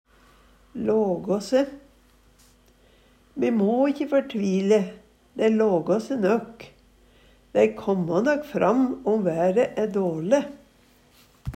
lågå se - Numedalsmål (en-US)